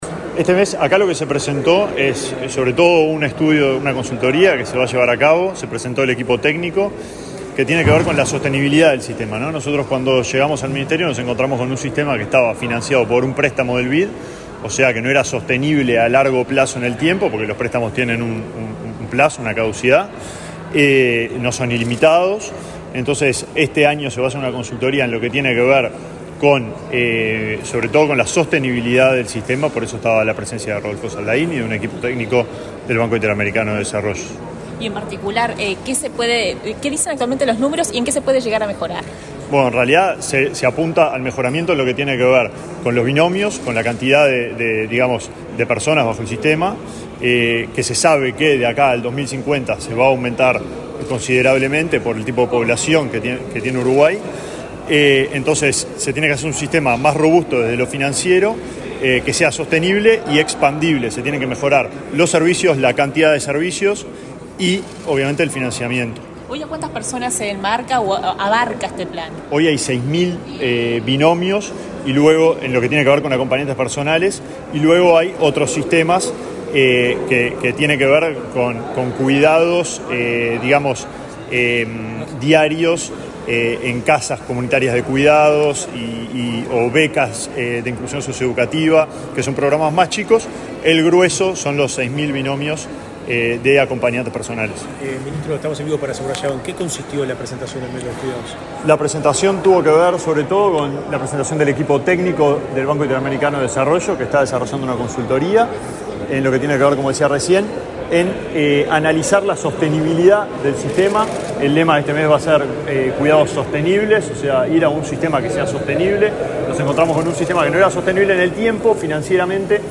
Declaraciones del ministro de Desarrollo Social, Alejandro Sciarra
El ministro de Desarrollo Social, Alejandro Sciarra, dialogó con la prensa, luego de participar, este lunes 1.°, en la Torre Ejecutiva, de la